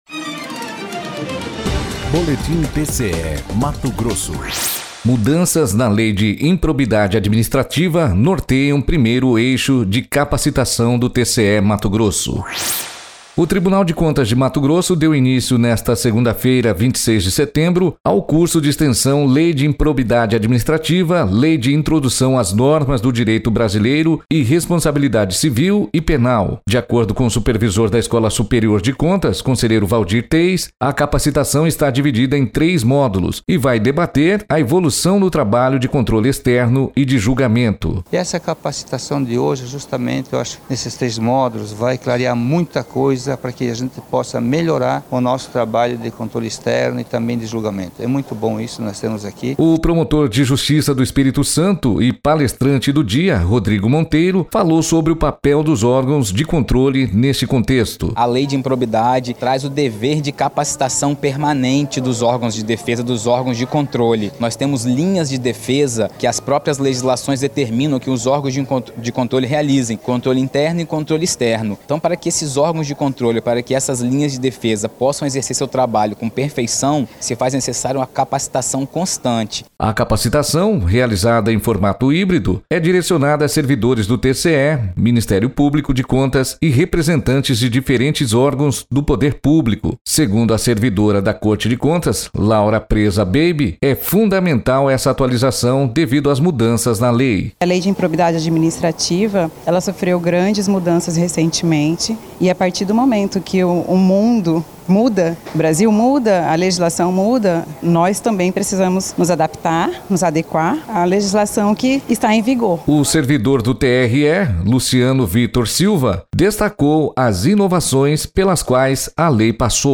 Sonora: Waldir Teis – conselheiro e supervisor da Escola Superior de Contas do TCE-MT
Sonora: Rodrigo Monteiro - promotor de Justiça do Espírito Santo e palestrante